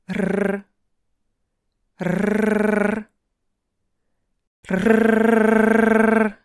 🔊 Hörprobe: Höre das harte russische R an:
r-russisch-hart.mp3